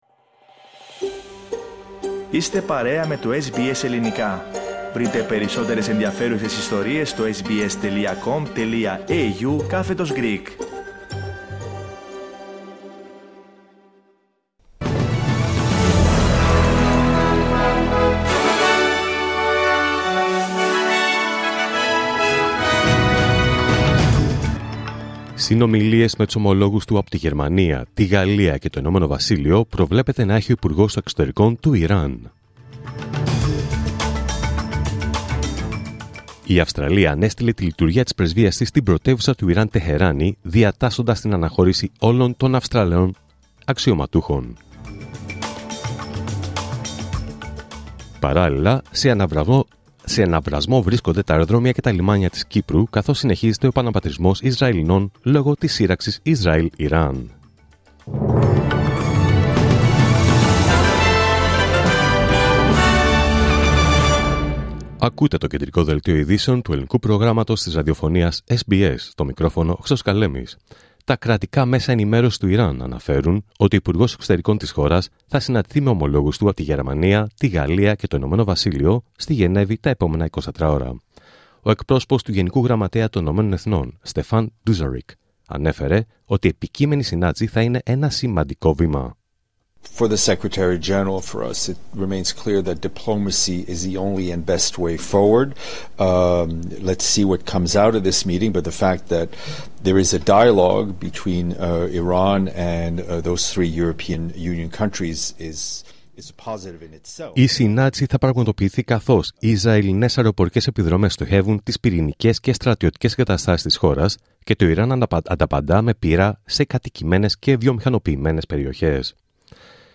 Δελτίο Ειδήσεων Παρασκευή 20 Ιουνίου 2025